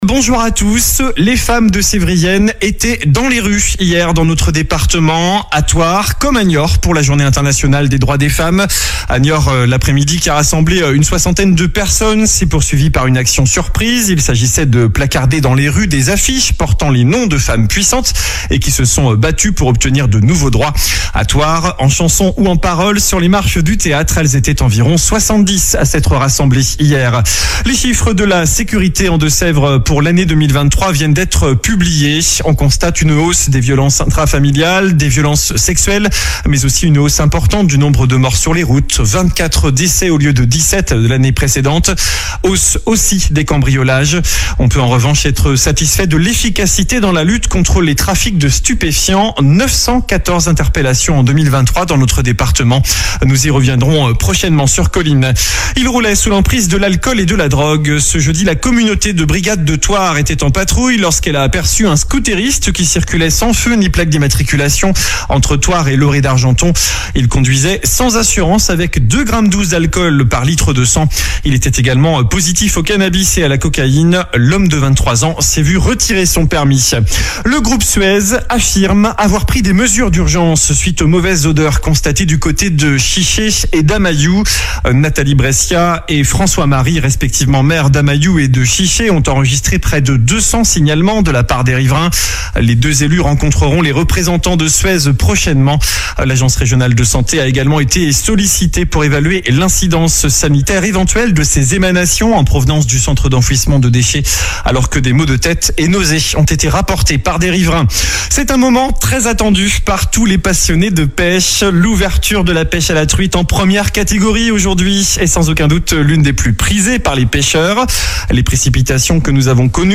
Journal du samedi 9 mars